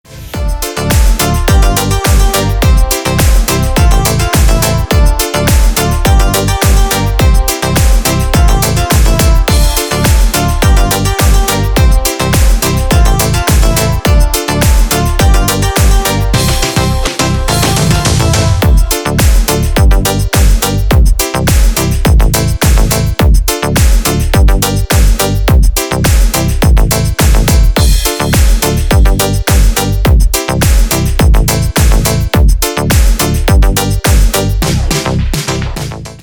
Dance рингтоны